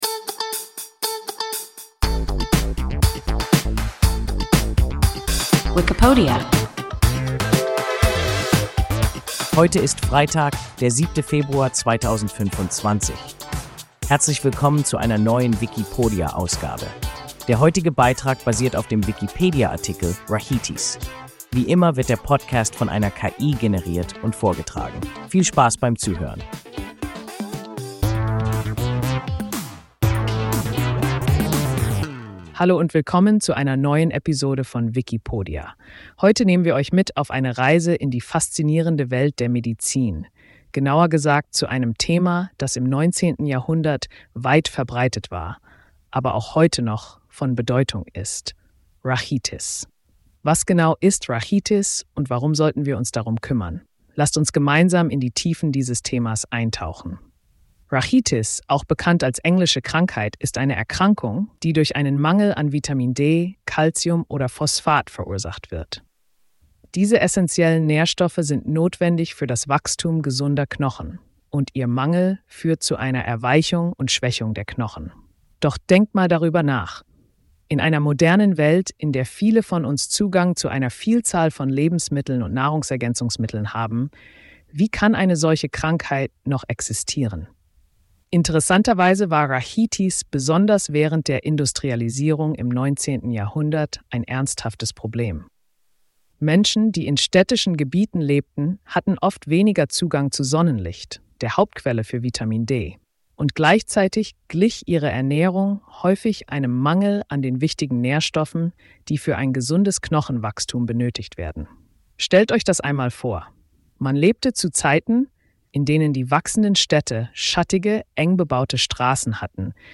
Rachitis – WIKIPODIA – ein KI Podcast